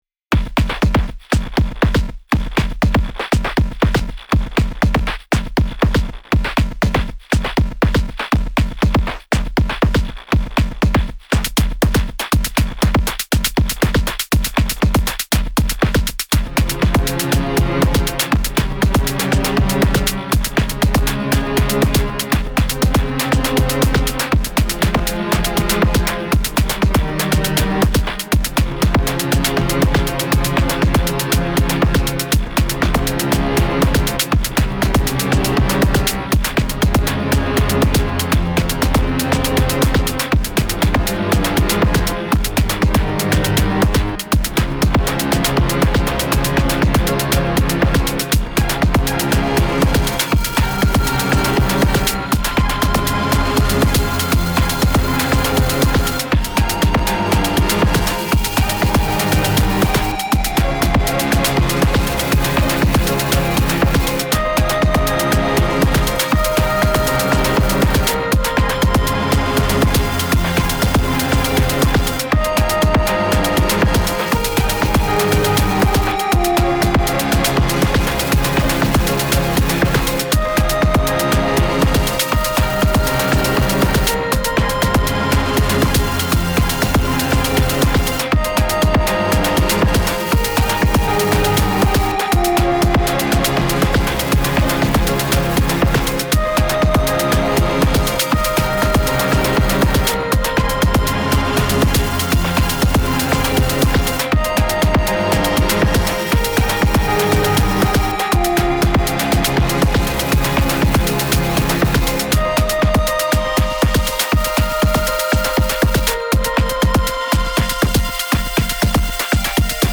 Played a bit with the FM-Drum machine & creating strings like stuff with OG FM & the comb+ so don’t mind the weird cheesy matchup. piano sound is one of the preset (studying the presets is the fastest way to learn these new machines and possibilities).
No p-locking, no sound-lock, just plain old step programming.
it sounds so dirty!